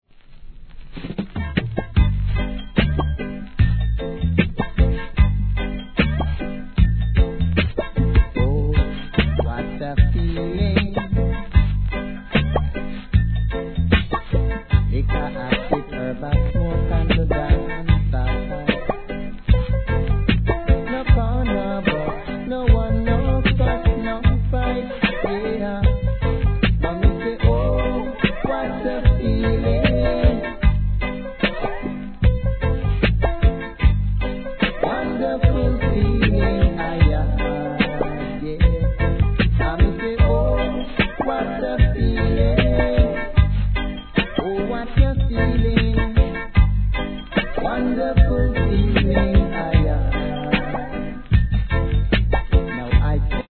REGGAE
'80s初期、ゆったりとしたリズムに自然と溶け込むように歌うさすがのヴォーカル♪